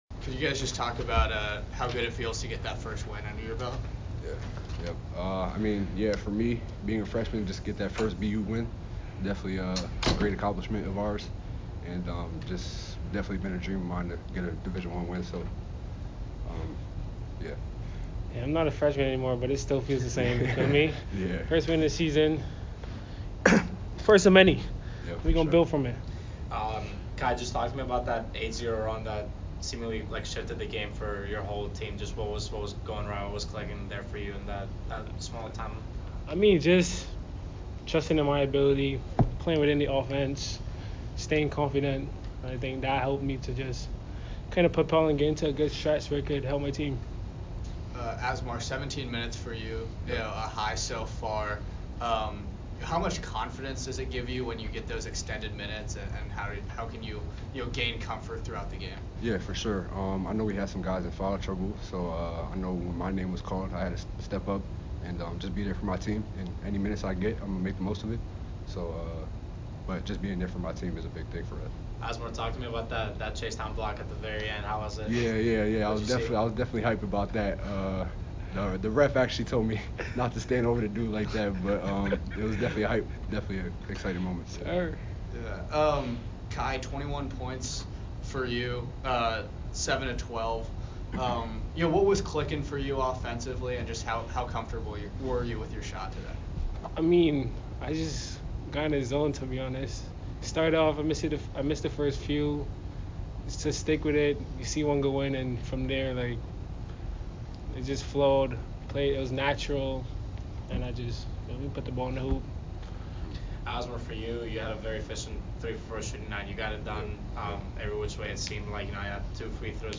Men's Basketball / Dartmouth Postgame Interview (11-16-24) - Boston University Athletics